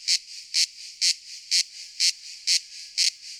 Add cicada sound (CC0)
sounds_cicada_05.ogg